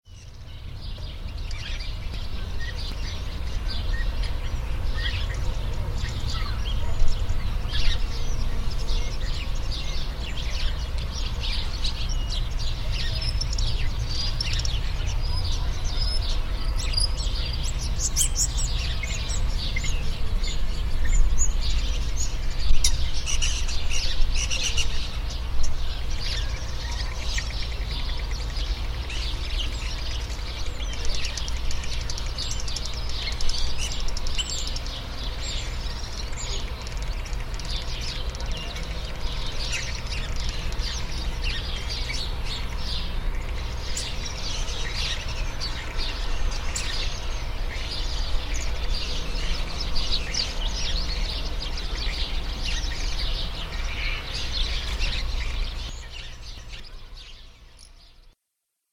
Ambiente de pueblo en Padiernos, Ávila
Sonido ambiente grabado en Padiernos, Ávila; se escuchan sonidos de pájaros, perros, viento, etc.